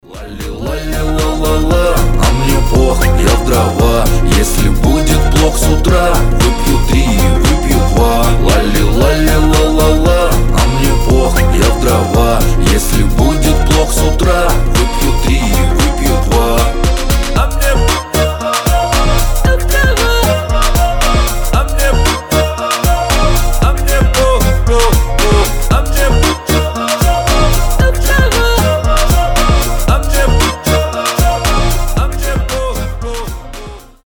• Качество: 320, Stereo
свист
веселые
аккордеон
мужские